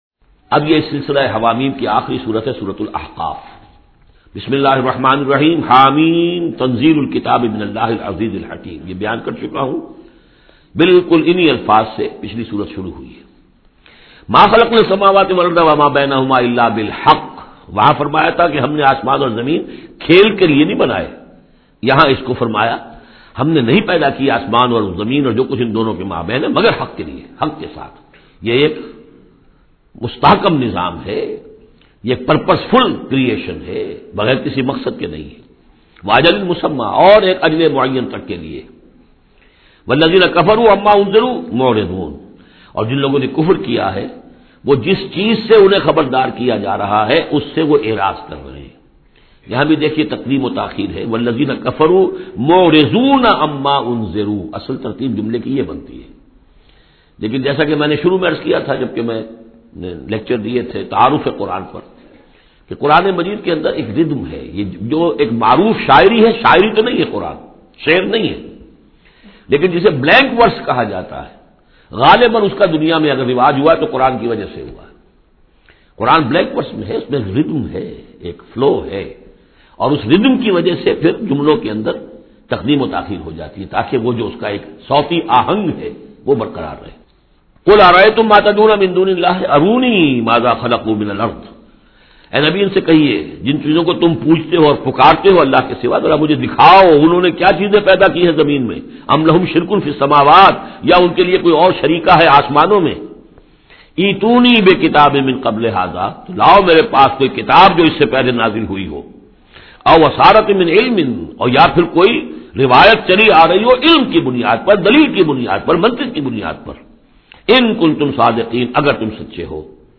Surah Al-Ahqaf is 46 Surah of Holy Quran. Listen online urdu tafseer of Surah Al-Ahqaf in the voice of Dr Israr Ahmed.